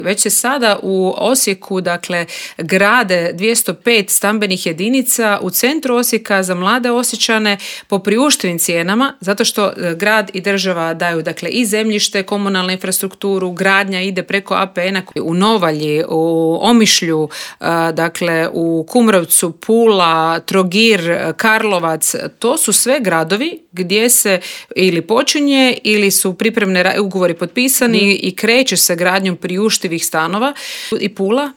Upravo o toj temi razgovarali smo u Intervjuu Media servisa s HDZ-ovom europarlamentarkom Nikolinom Brnjac.